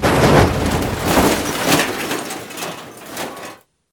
clamp2.ogg